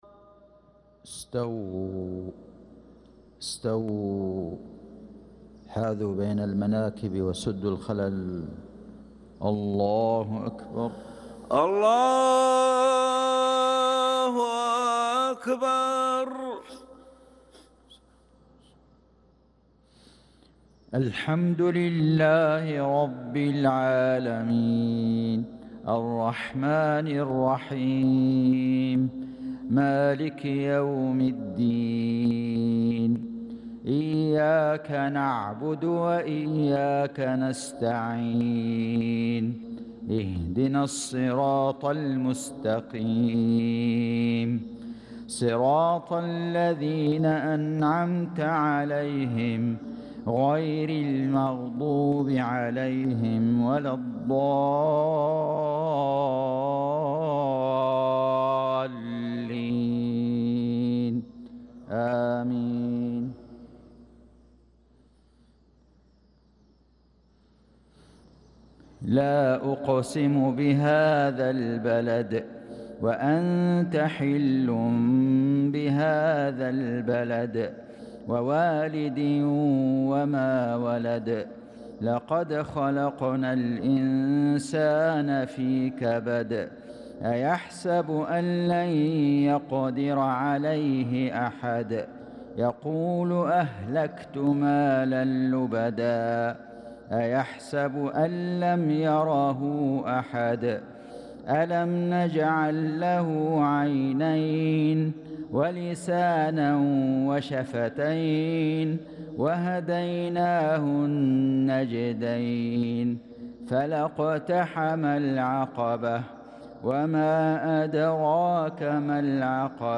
صلاة المغرب للقارئ فيصل غزاوي 19 ذو القعدة 1445 هـ
تِلَاوَات الْحَرَمَيْن .